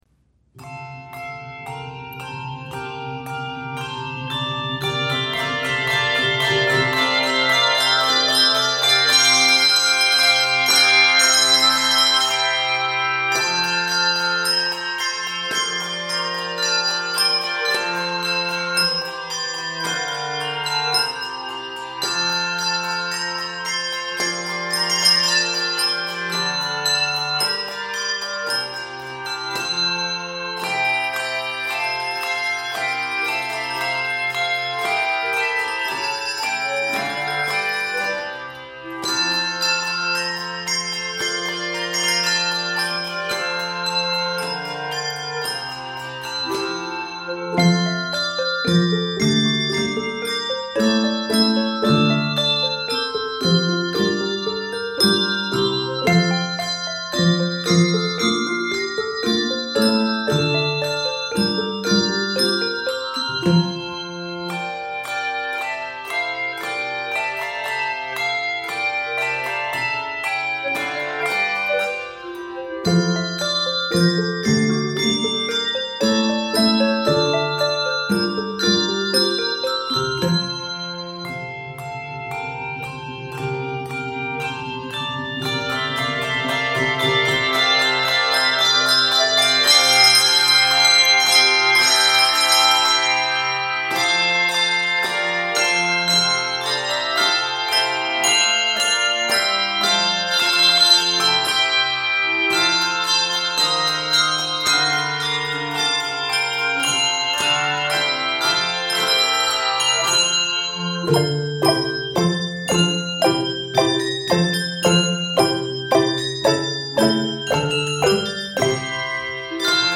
jubilant setting
handbell techniques
Key of F Major.